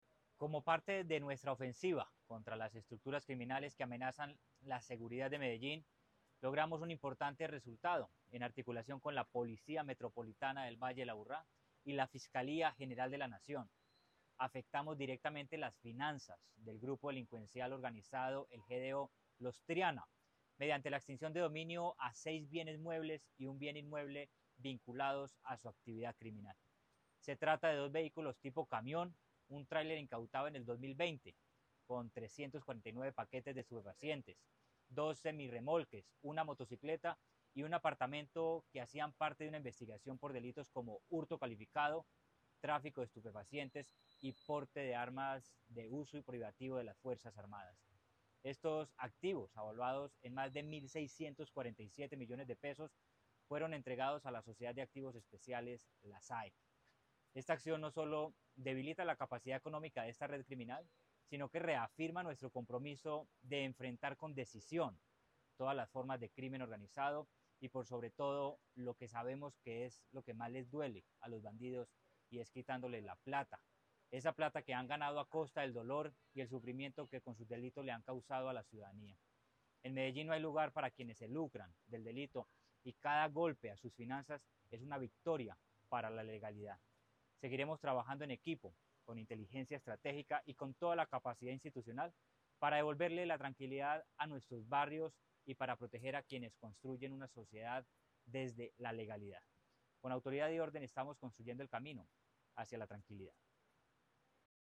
Declaraciones-del-secretario-de-Seguridad-y-Convivencia-Manuel-Villa-Mejia.mp3